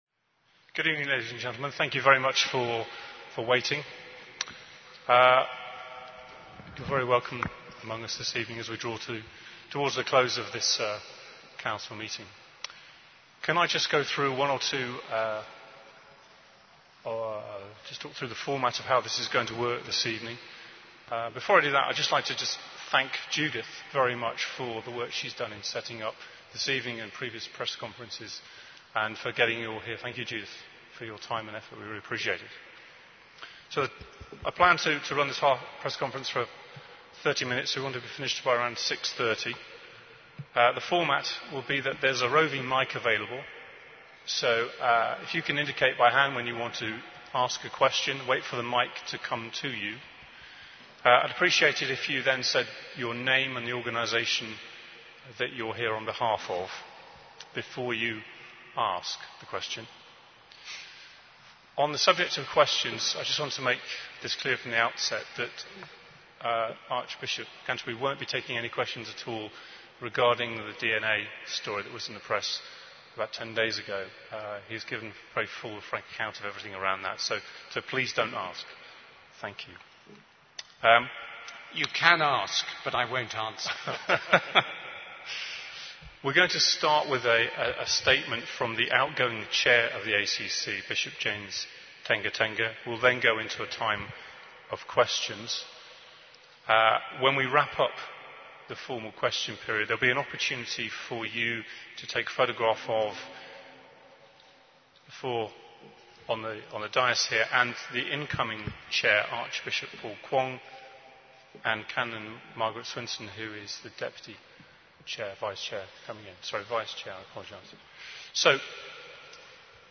ACC16 Press Conference 18th April 2016
acc16-press-conference-2.mp3